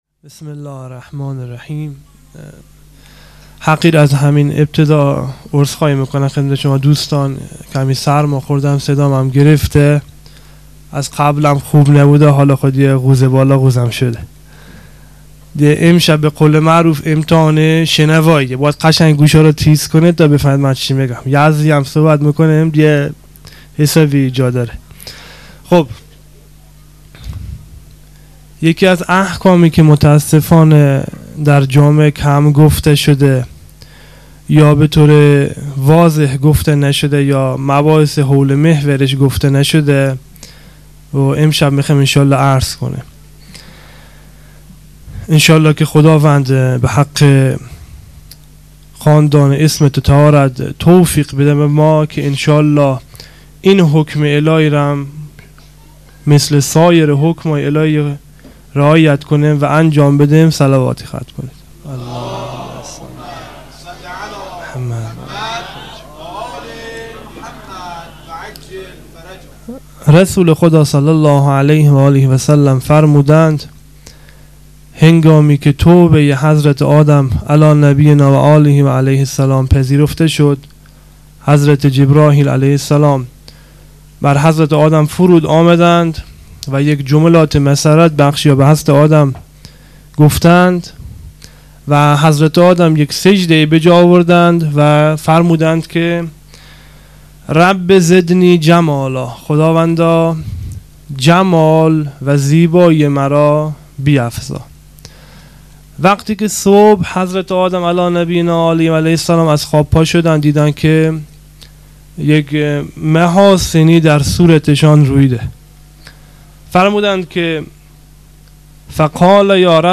خیمه گاه - هیئت مکتب الزهرا(س)دارالعباده یزد